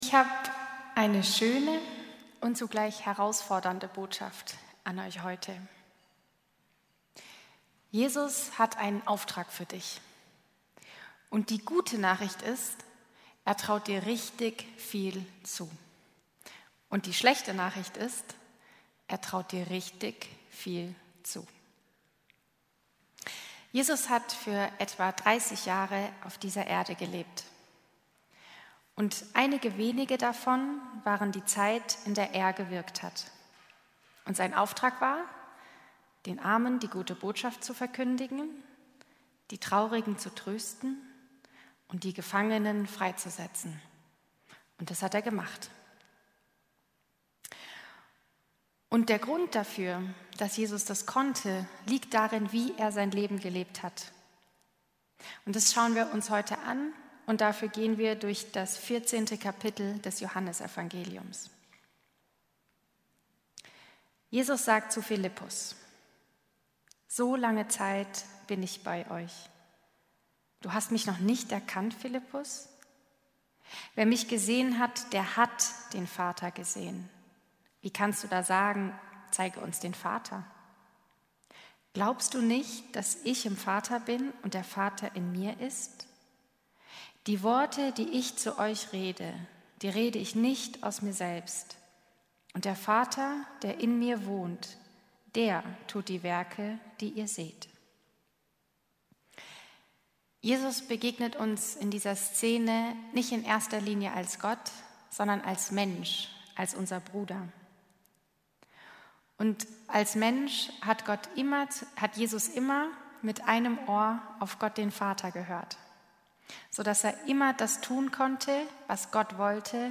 diesem Gottesdienst genauer erforschen.